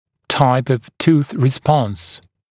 [taɪp əv tuːθ rɪ’spɔns][тайп ов ту:с ри’спонс]тип ответной реакции зуба (зубов)